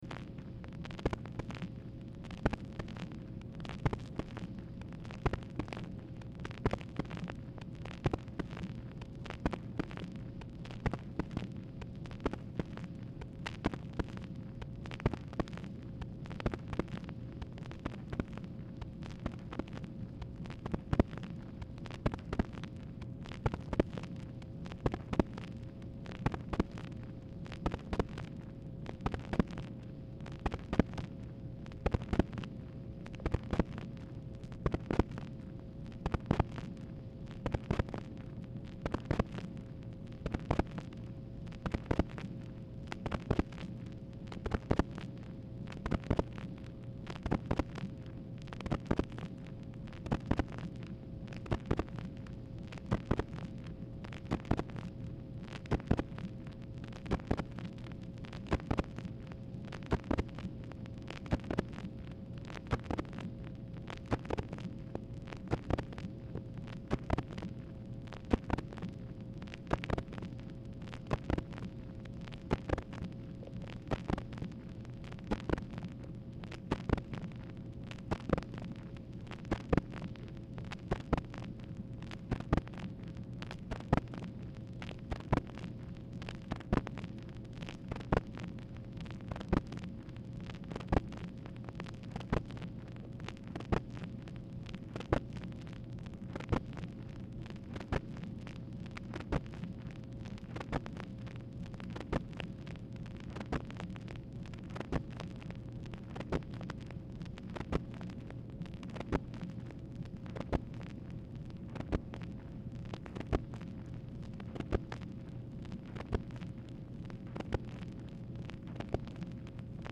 Telephone conversation # 4740, sound recording, MACHINE NOISE, 8/5/1964, time unknown | Discover LBJ
Format Dictation belt
Specific Item Type Telephone conversation